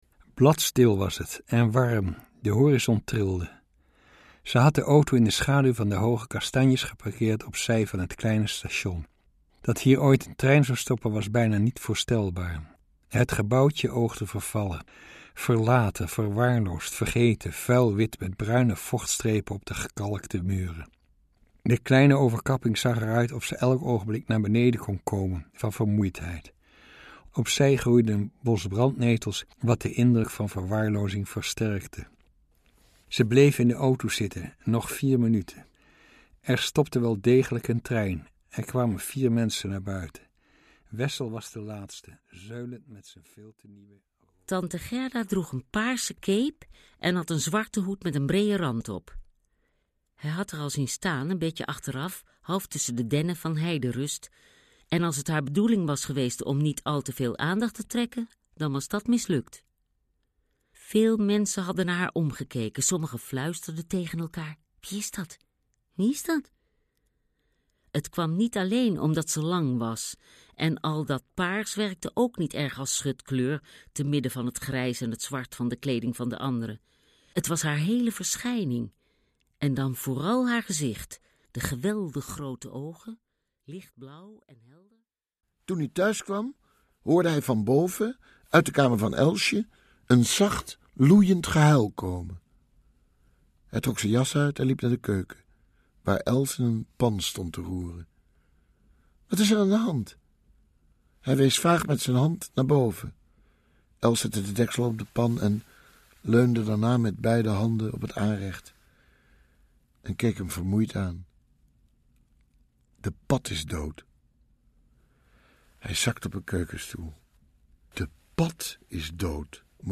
Derk Bolt sprak zijn hele boek Altijd ergens anders zelf in.